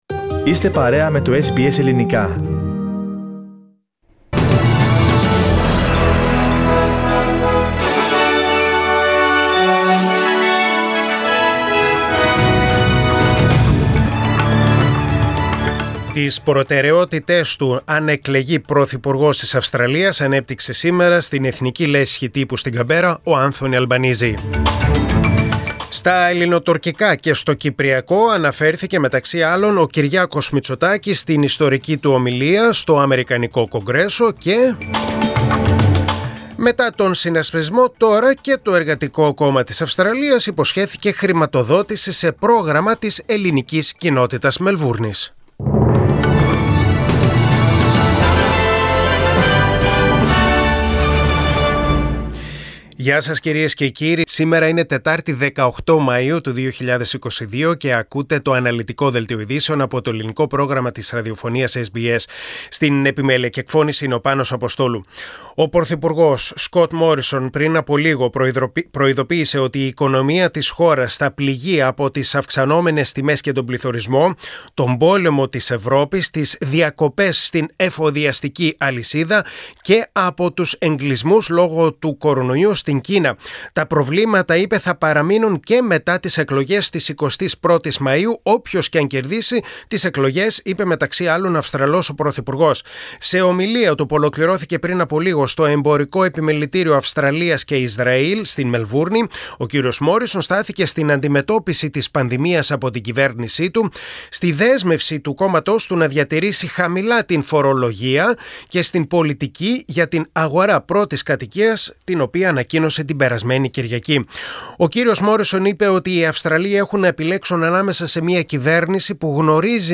Δελτίο Ειδήσεων: Τετάρτη 18.5.2022